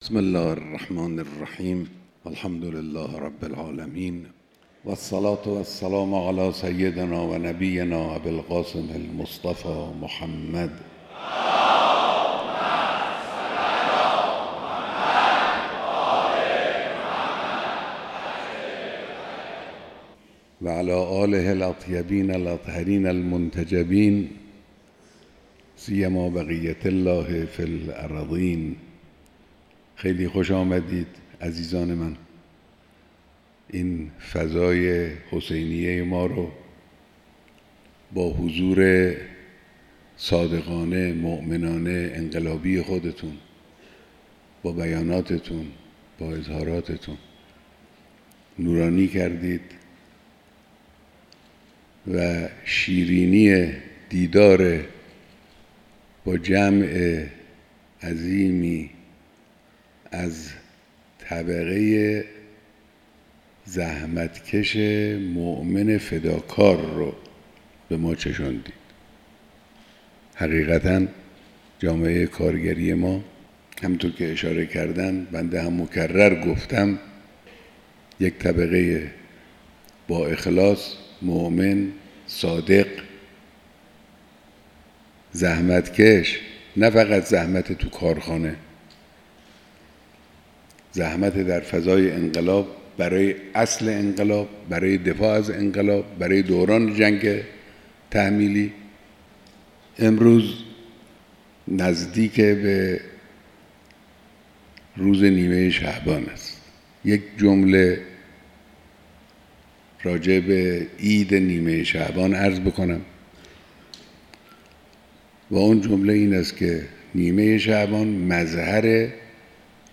بیانات رهبر انقلاب در دیدار کارگران